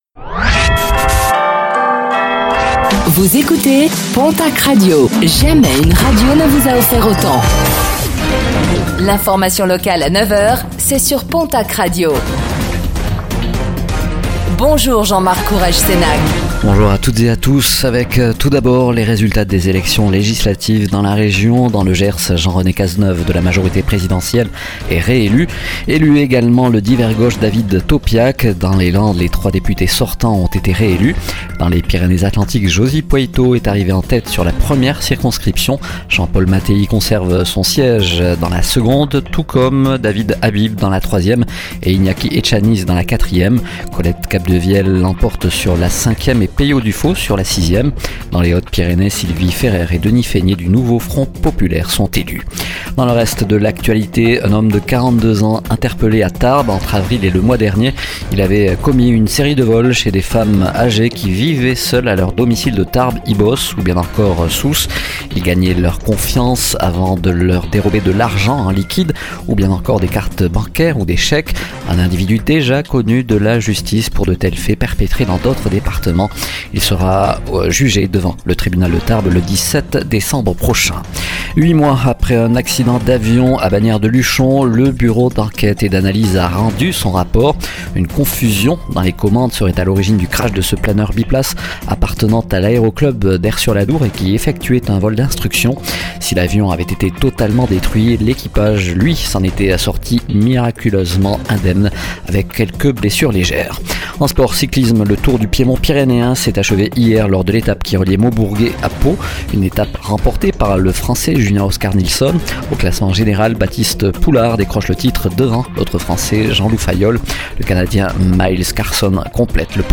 Réécoutez le flash d'information locale de ce lundi 08 juillet 2024